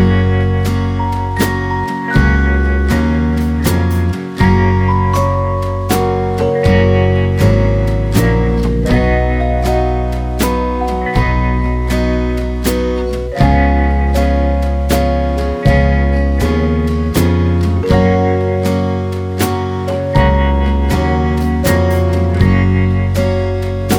no Backing Vocals Easy Listening 2:58 Buy £1.50